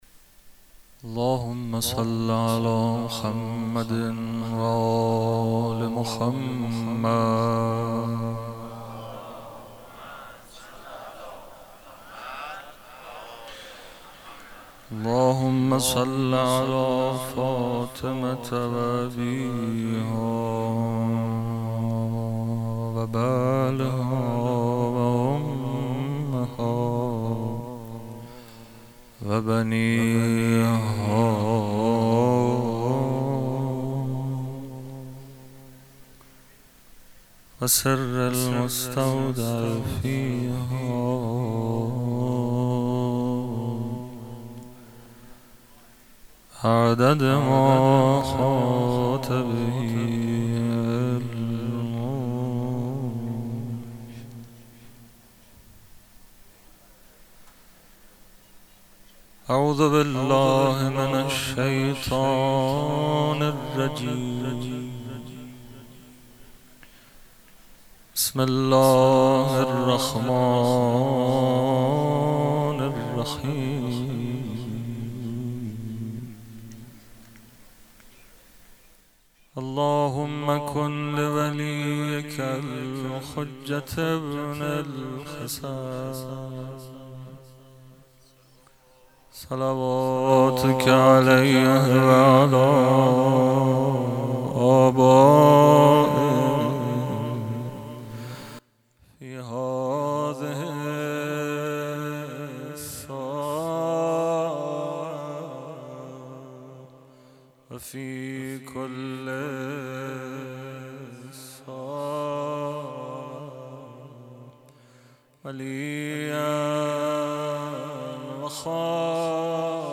پیش منبر